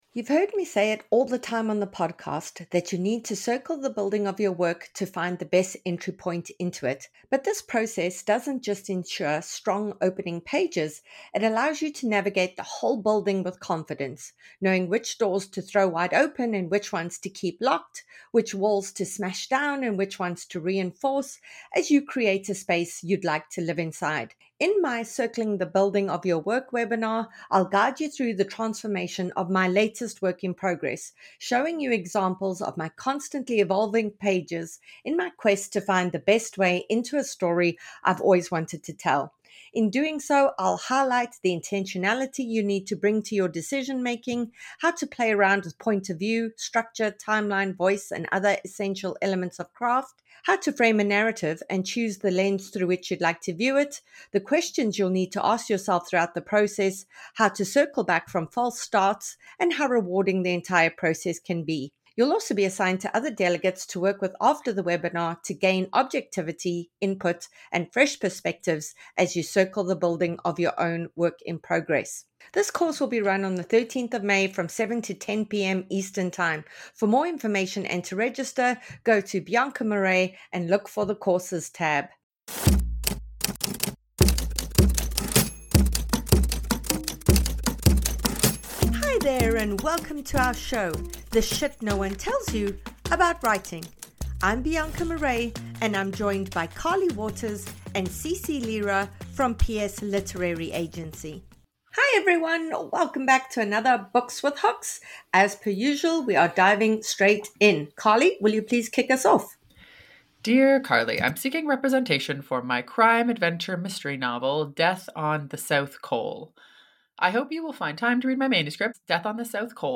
In this inspiring author interview